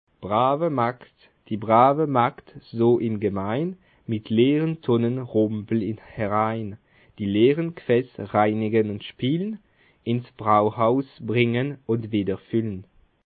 Bas Rhin
Ville Prononciation 67
Schiltigheim